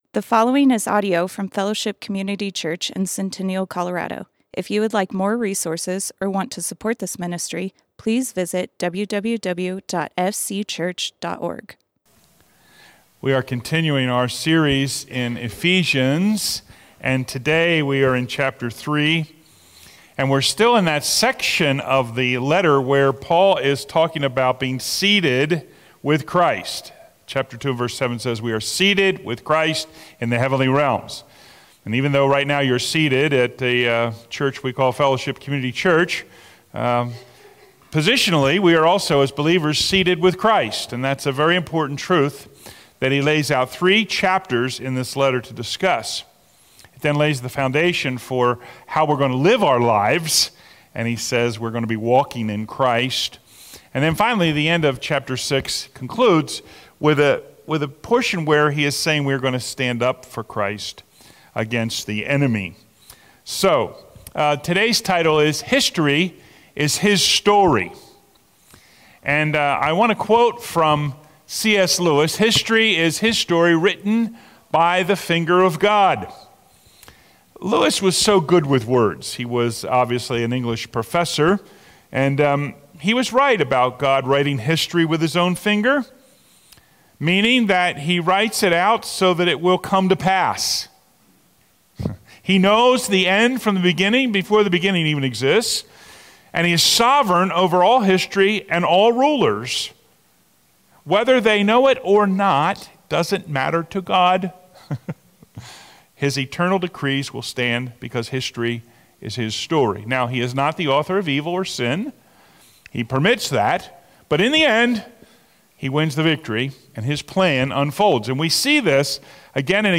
Fellowship Community Church - Sermons History is His Story Play Episode Pause Episode Mute/Unmute Episode Rewind 10 Seconds 1x Fast Forward 30 seconds 00:00 / 30:06 Subscribe Share RSS Feed Share Link Embed